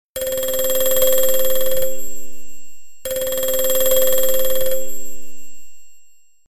Ringer.ogg